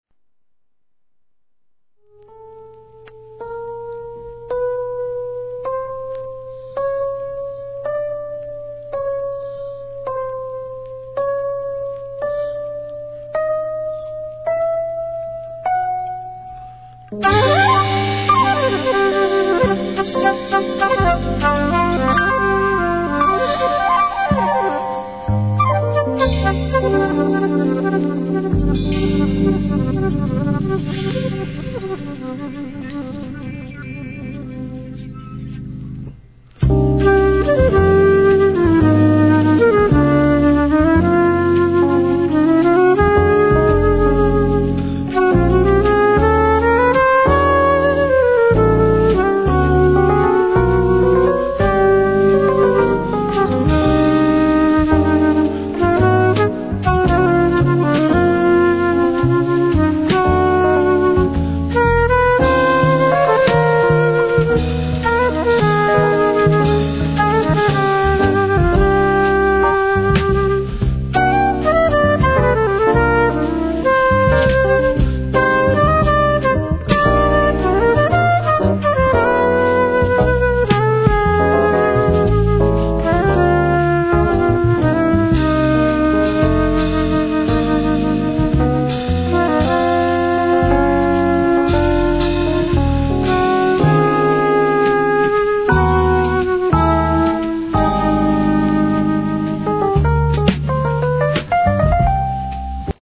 ¥ 1,430 税込 関連カテゴリ SOUL/FUNK/etc...